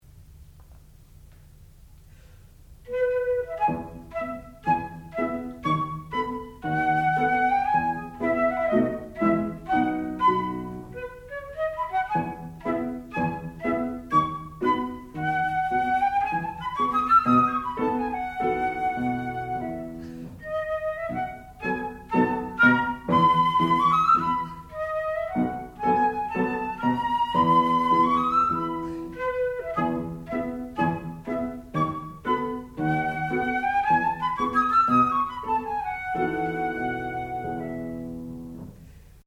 classical music
piano
Advanced Recital
flute